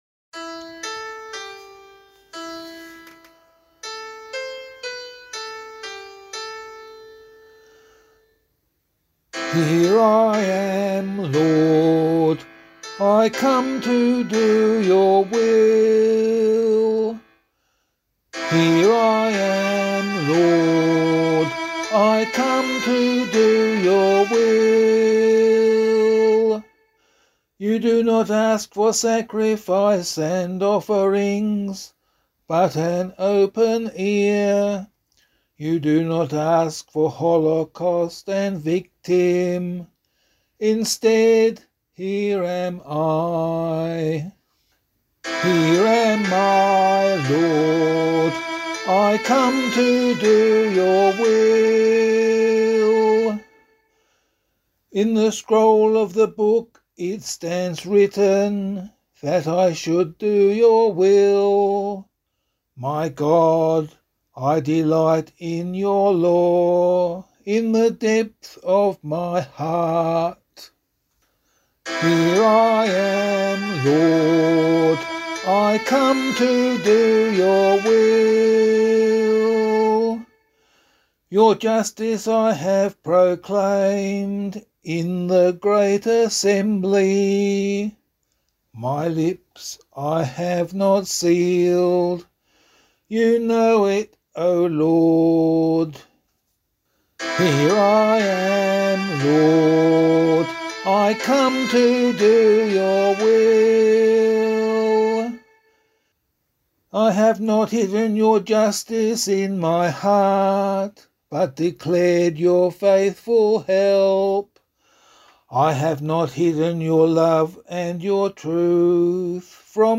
184 Annunciation Psalm [LiturgyShare 4 - Oz] - vocal.mp3